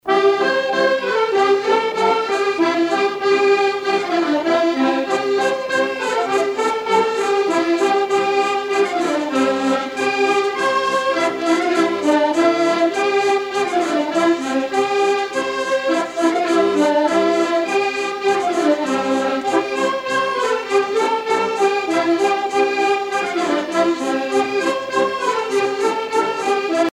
danse : polka piquée